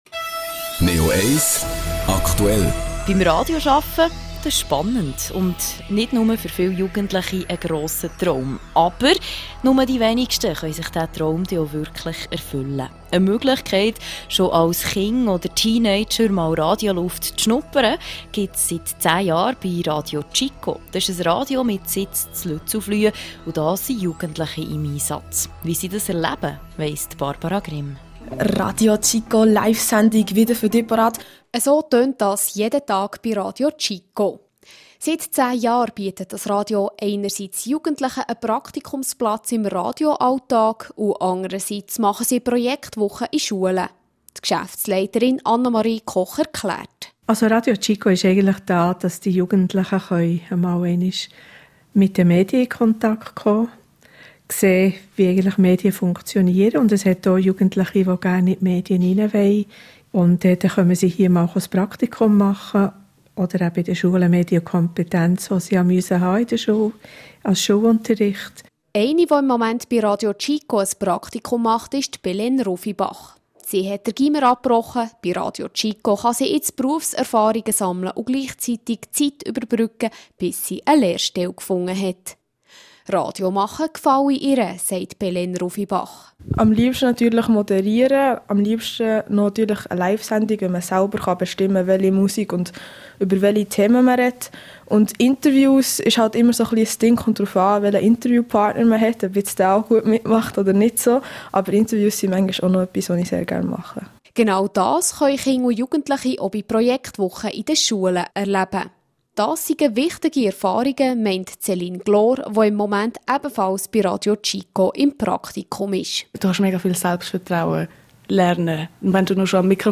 Am 8. Januar haben wir in unserem Studio den 10. Geburtstag von RadioChico Schweiz gefeiert.
Interview von Radio Neo mit den